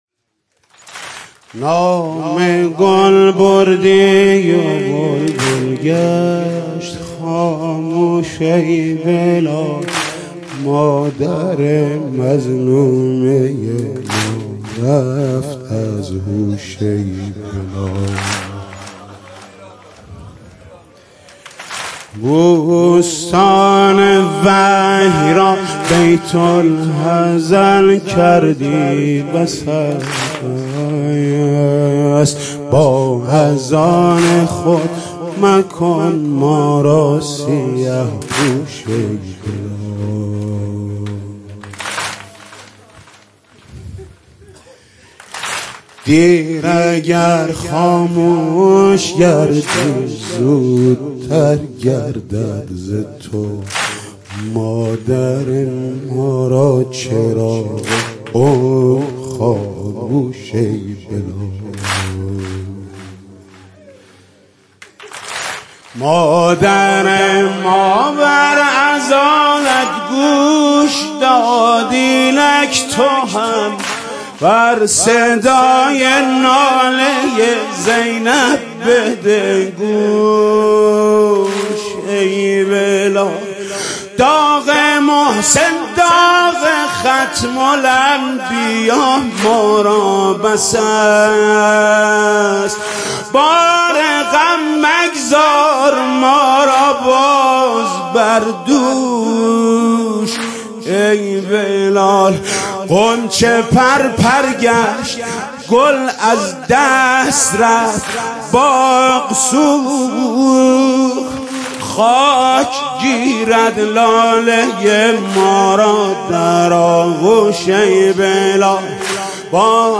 مداحی و نوحه
مـراسم عـزادارى شـب سوّم فاطمیه اول